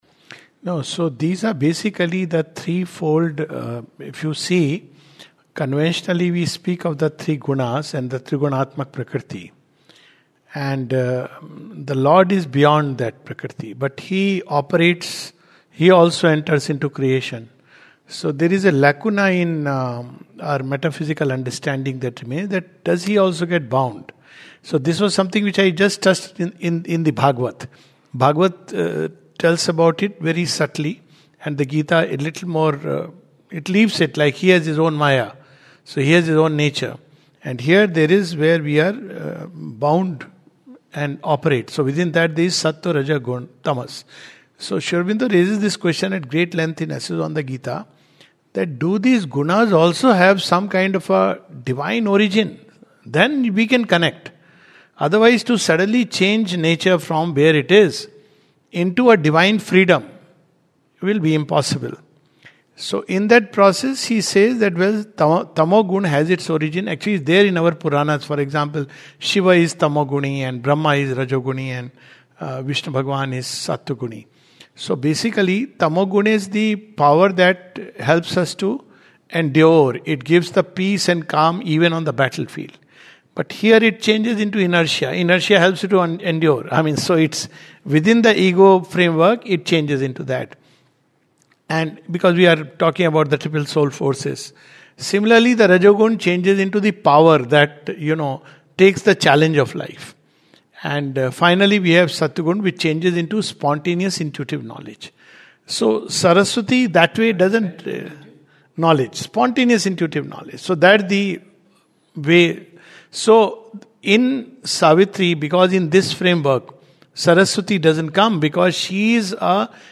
A session with the Uditam Group of Sri Aurobindo Society took up questions about the psychic being, the Atman, Supramental creation, children today, action of the Supramental Force etc.